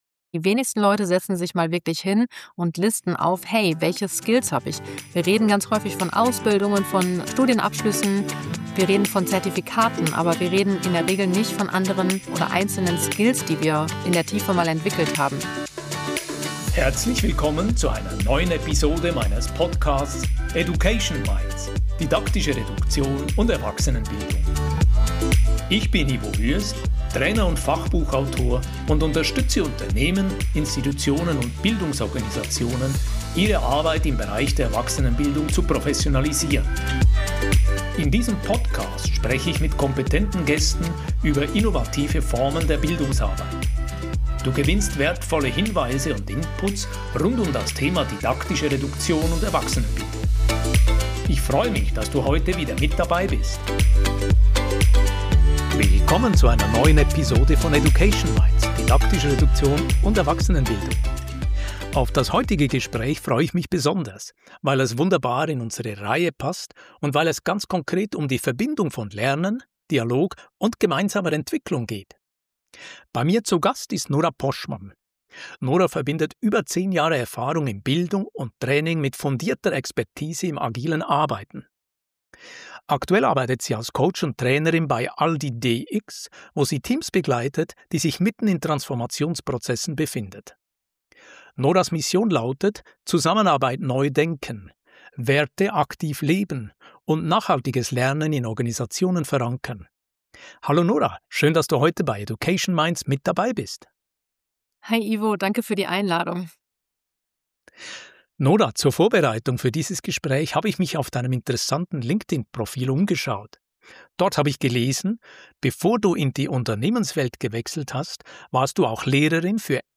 Im Gespräch wird deutlich: Berufliche Veränderungen bedeuten nicht, bei null zu beginnen. Vielmehr geht es darum, vorhandene Kompetenzen zu erkennen, zu übersetzen und in neue Kontexte zu übertragen.